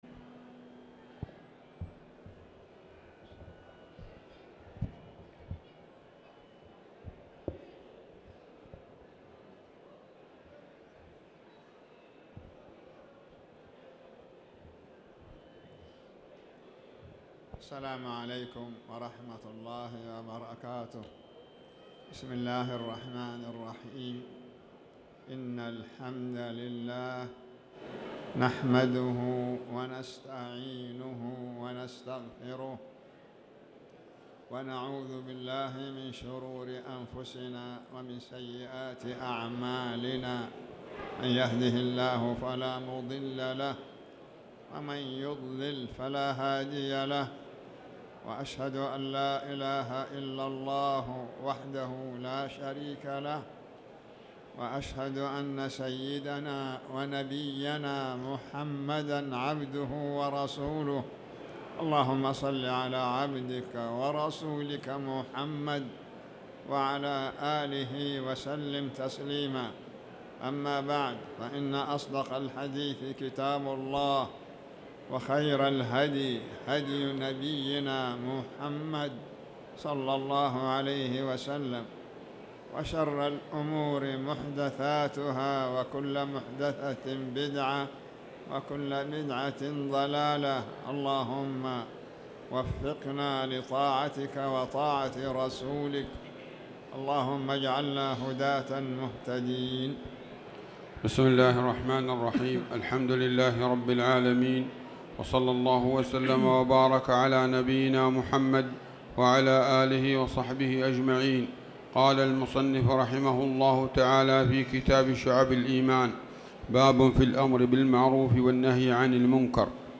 تاريخ النشر ٧ رجب ١٤٣٨ هـ المكان: المسجد الحرام الشيخ